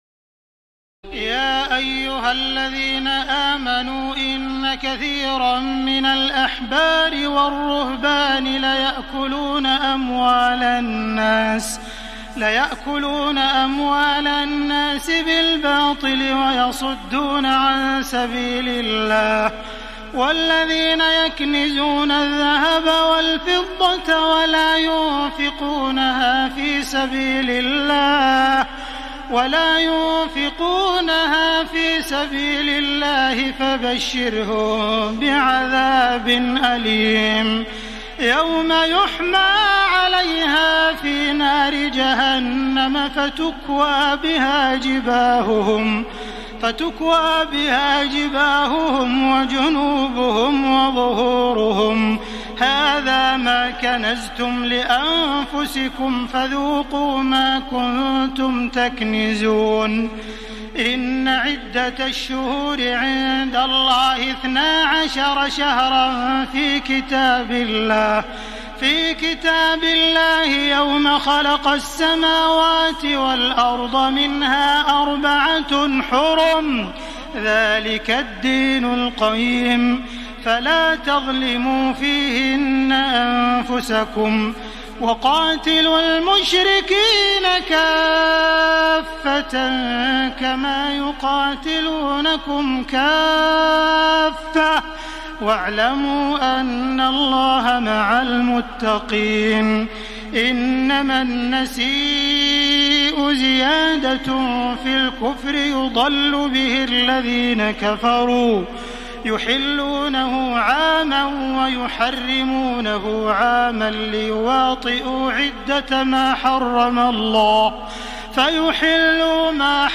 تراويح الليلة التاسعة رمضان 1433هـ من سورة التوبة (34-93) Taraweeh 9 st night Ramadan 1433H from Surah At-Tawba > تراويح الحرم المكي عام 1433 🕋 > التراويح - تلاوات الحرمين